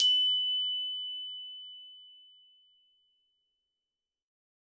Hard_plastic_f_F#5.wav